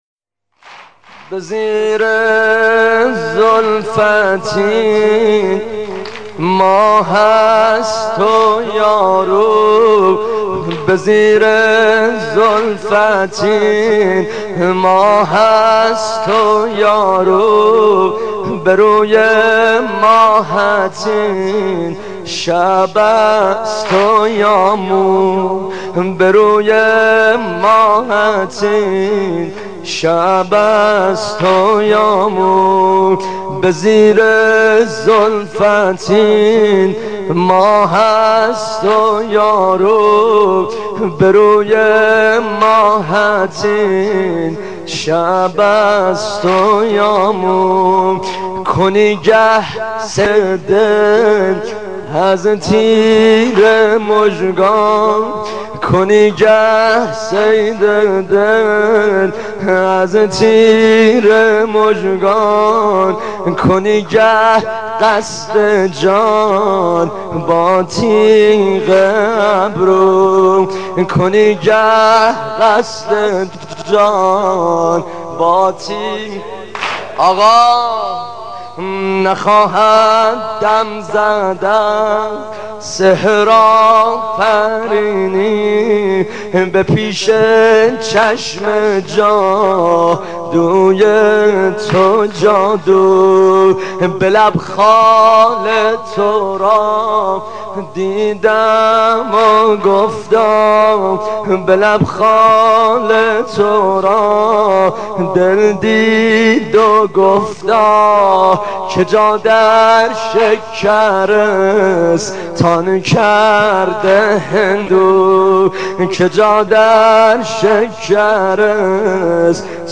دانلود مداحی